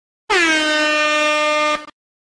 airhorn.ogg